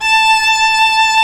Index of /90_sSampleCDs/Roland - String Master Series/STR_Viola Solo/STR_Vla1 % + dyn